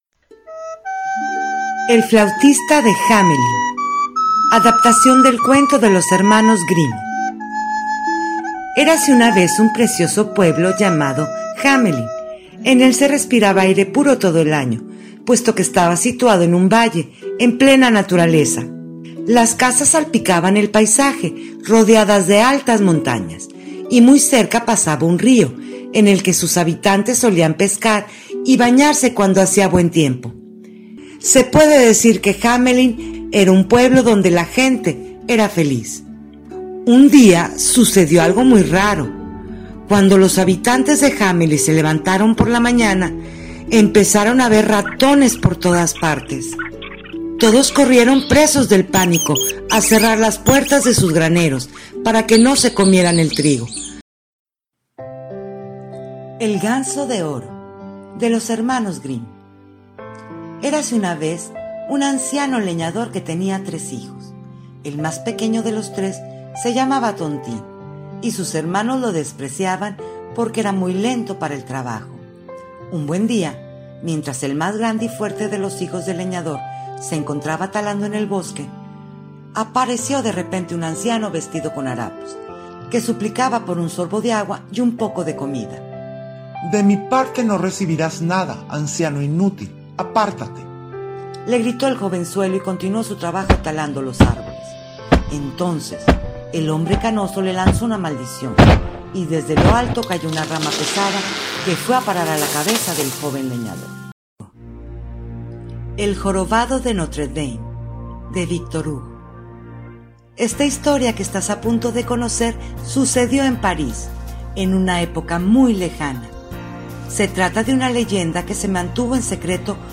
Entretenidos Audiocuentos de los cuentos clásicos, con
efectos de sonido y diferentes voces.